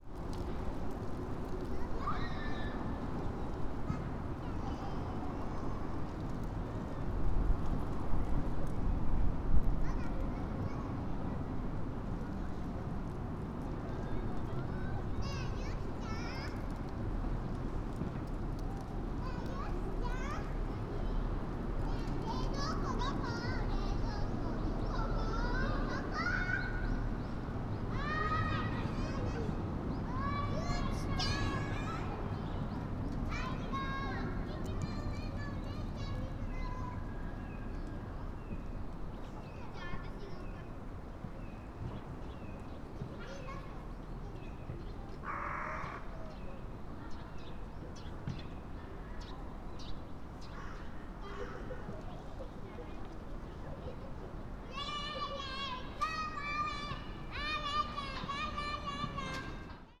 Fukushima Soundscape: Shinhama Park